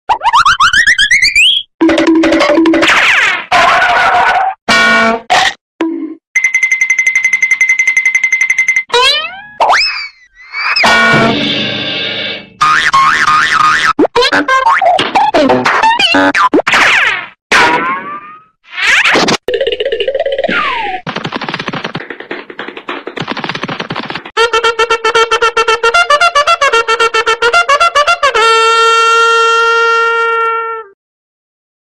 Goofy Cartoon Sound Effect Free Download
Goofy Cartoon